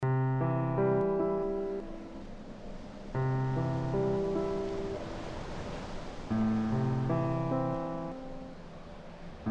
Synthogy Ivory Grand Piano - ����� �������� � ����� ������ ����������